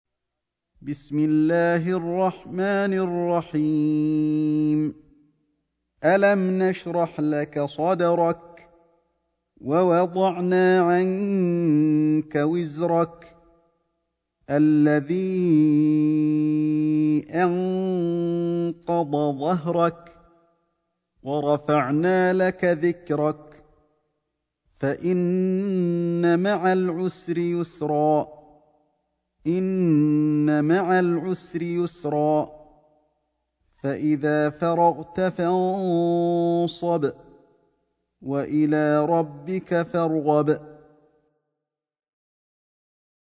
سورة الشرح > مصحف الشيخ علي الحذيفي ( رواية شعبة عن عاصم ) > المصحف - تلاوات الحرمين